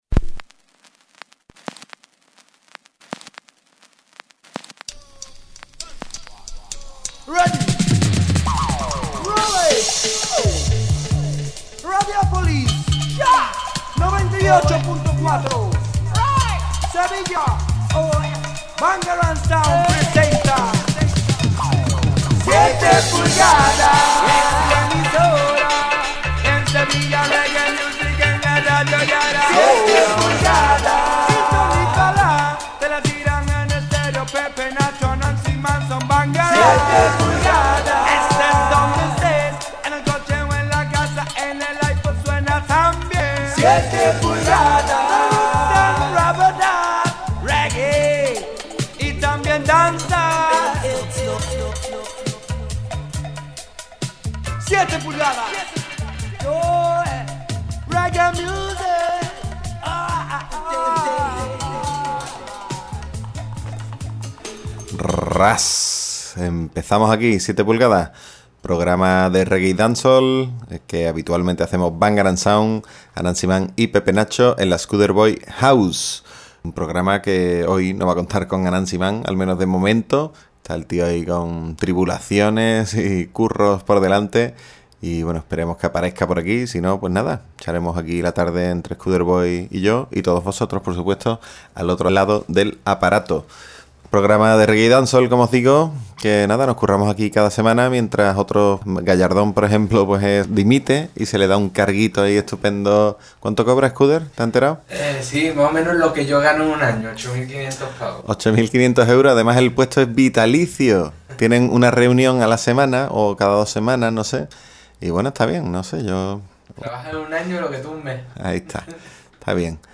Ya podéis escuchar el programa 303 de 7PULGADAS, emitido este pasado viernes 3 de Octubre. Presentado y dirigido por la Bangarang Sound y grabado en la Skuderbwoy House.